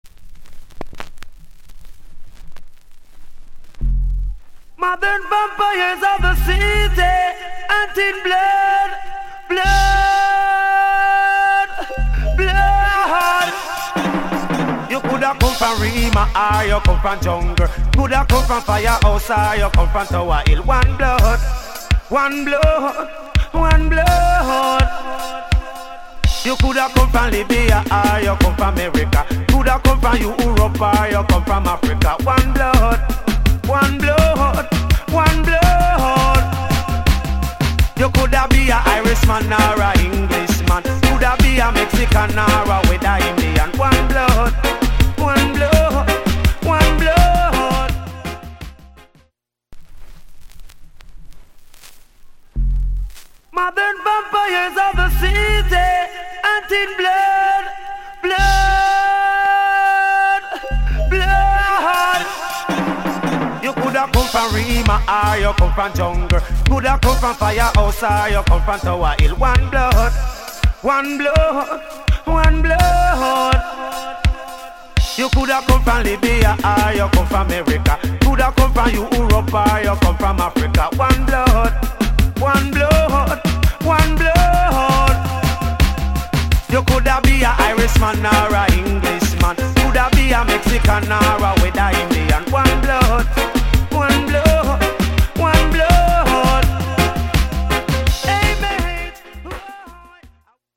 *'89 Big Hit Dance Hall Classic